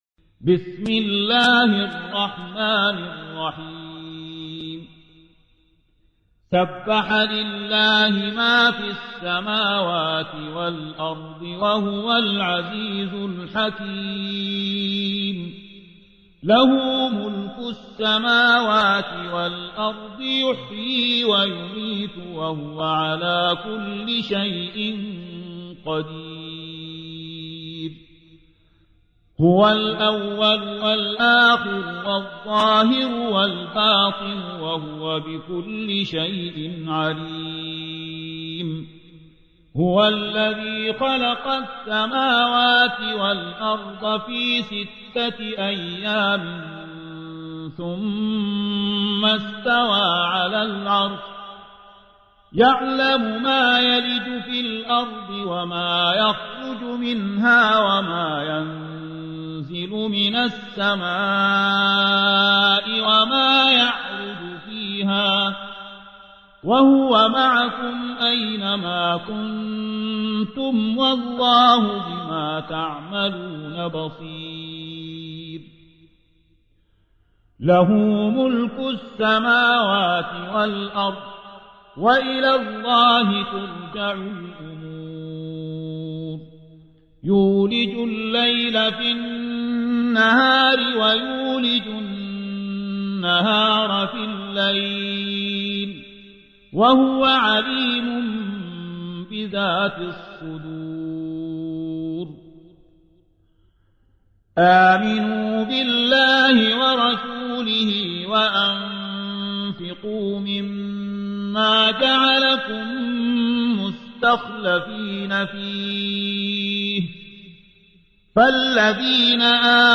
57. سورة الحديد / القارئ